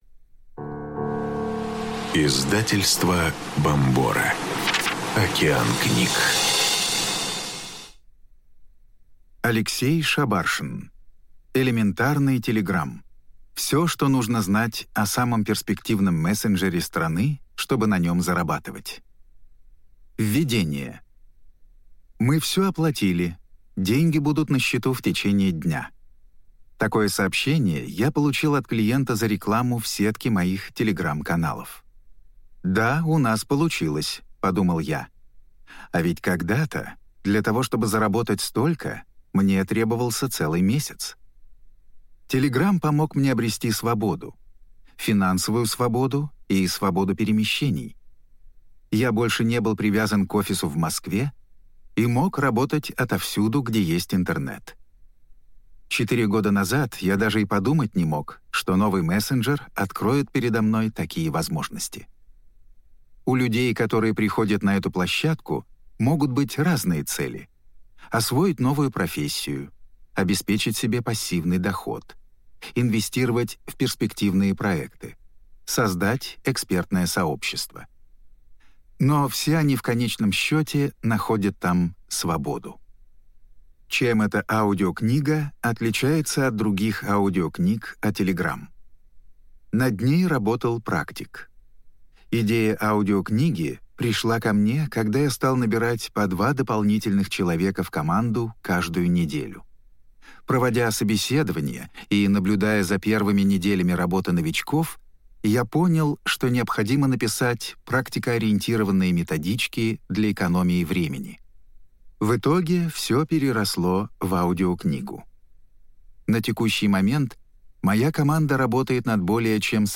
Аудиокнига Элементарный TELEGRAM. Все, что нужно знать о самом перспективном мессенджере страны, чтобы на нем зарабатывать | Библиотека аудиокниг